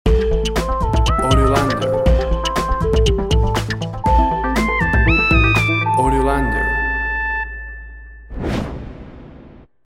elctronic underground sounds and urban character.
WAV Sample Rate 16-Bit Stereo, 44.1 kHz
Tempo (BPM) 150